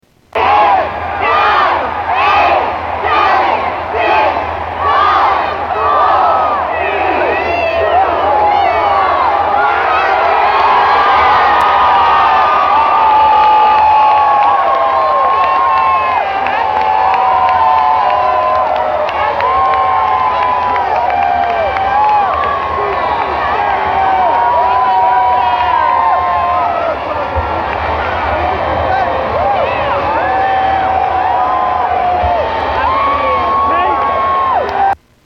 New Years in New York
Tags: New York New York city New York city sounds NYC Travel